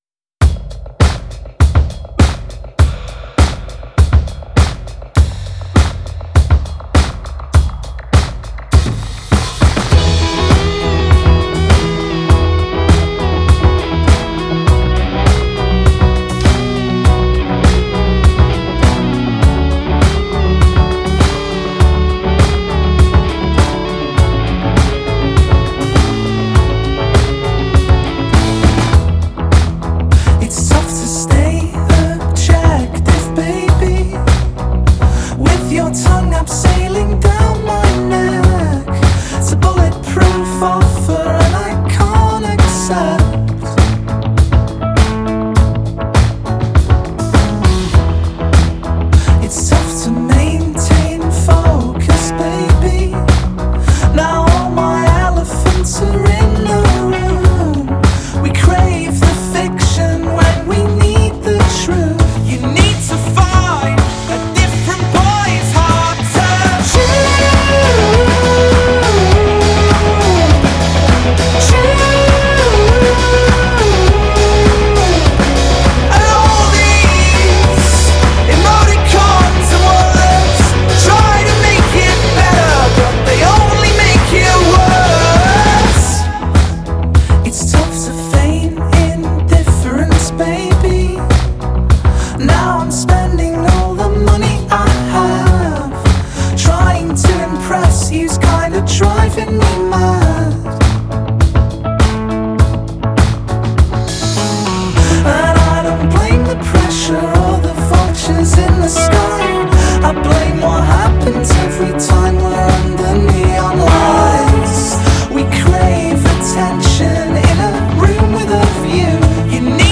With an obviously teen-friendly sound